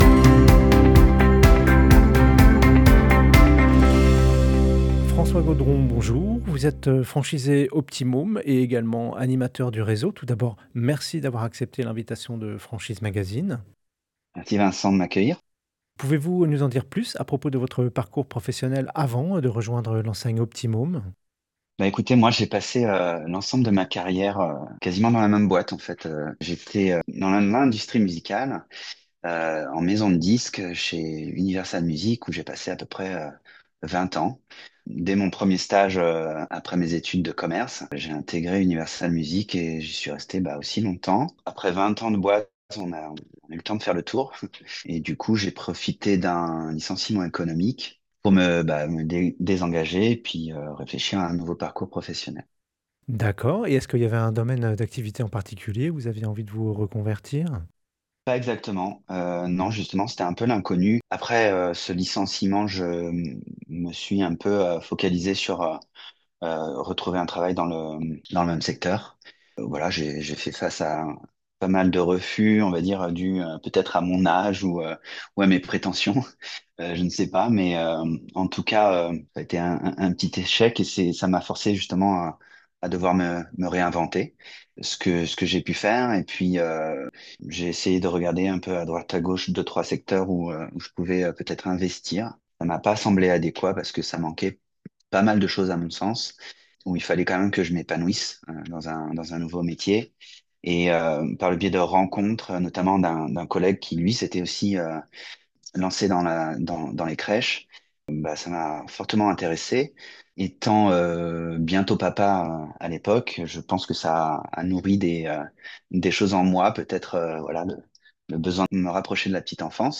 Au micro du podcast Franchise Magazine : la Franchise Ô P’tit Môme - Écoutez l'interview